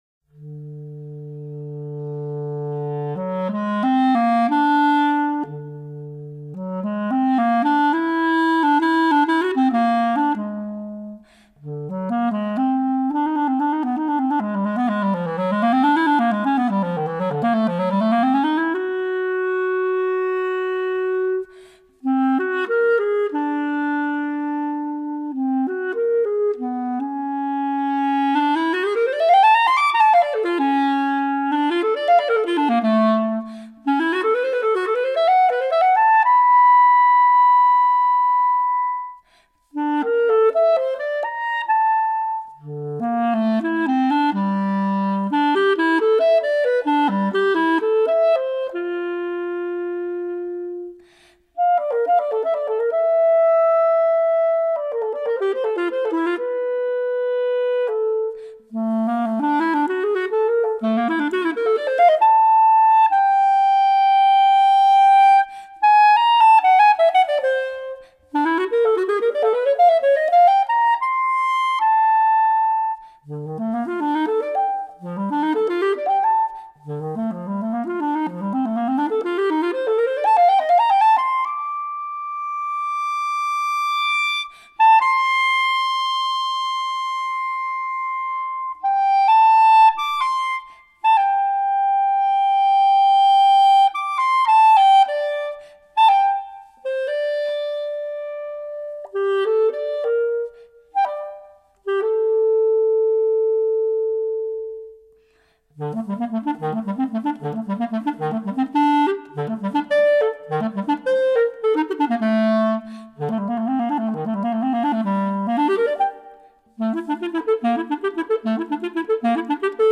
Bb Clarinet Solo